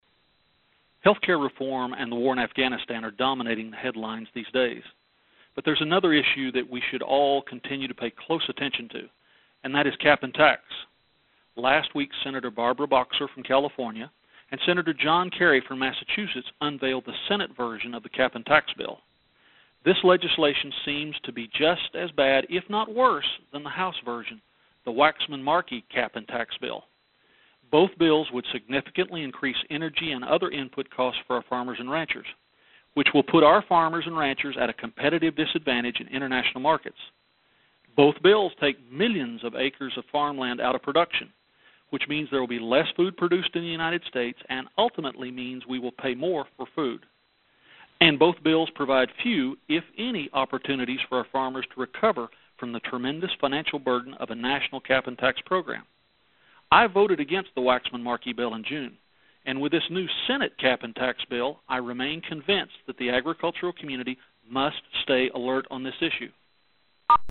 The Ag Minute is Ranking Member Lucas' weekly radio address that is released each Tuesday from the House Agriculture Committee Republicans.